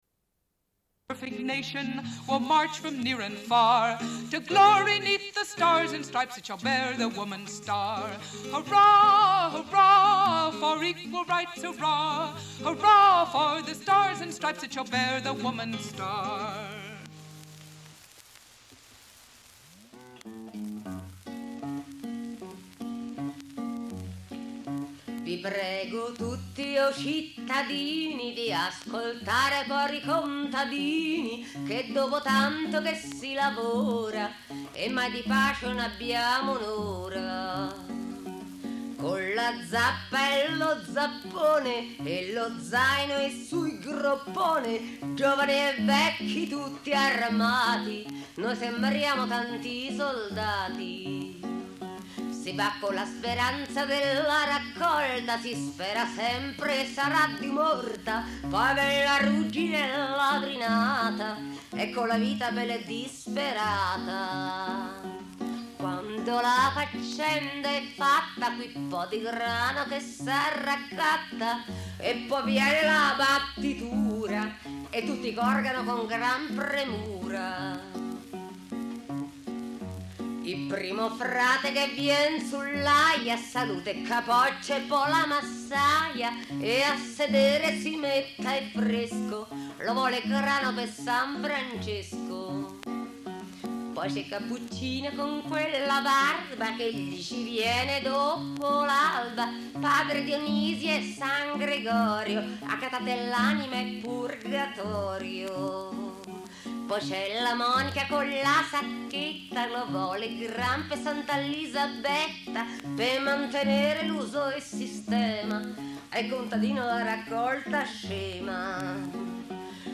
Une cassette audio, face B21:59